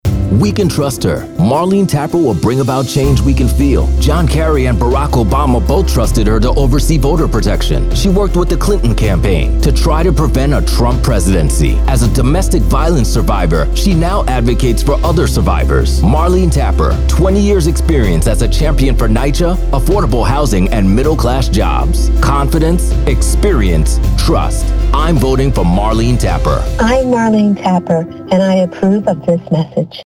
African American, Diverse Political Commercial Voice Over
Profound. Resonant. Real | Voiceovers